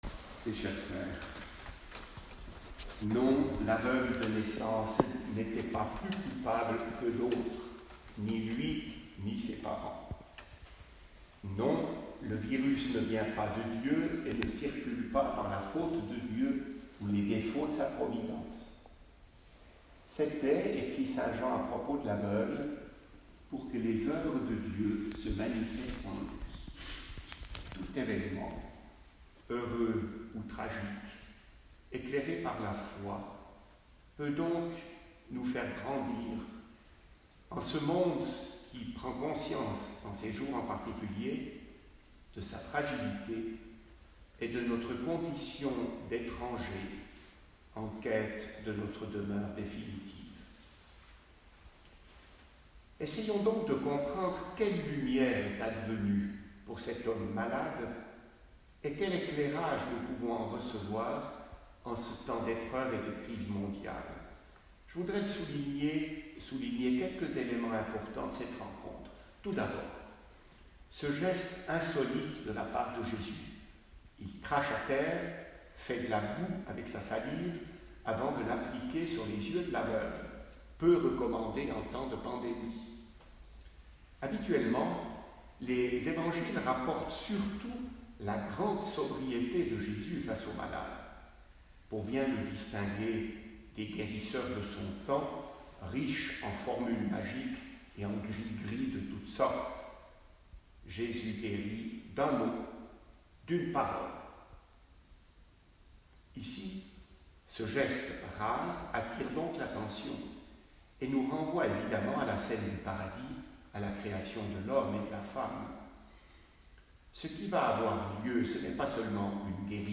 Aujourd'hui, pour la deuxième fois, nous avons célébré la messe à huis clos dans notre église.